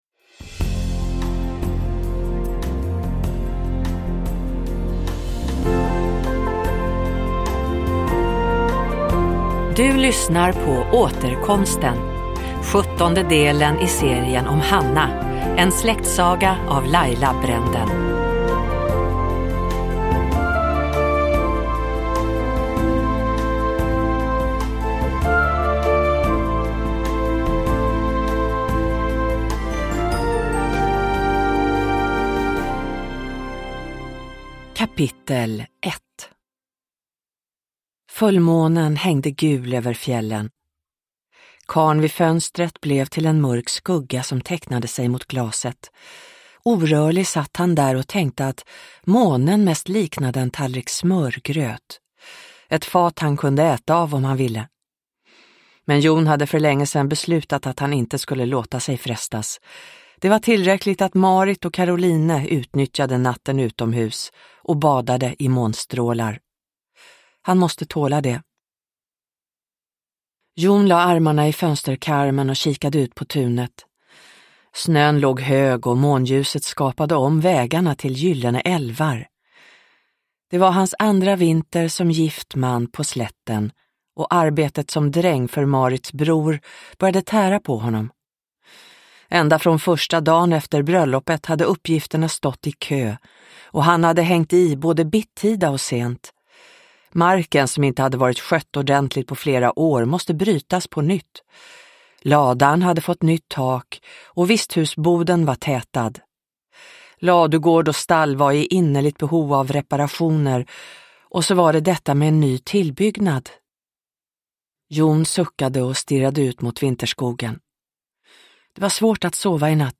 Återkomsten – Ljudbok